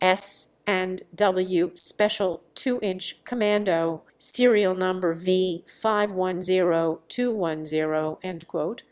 autotune_E.wav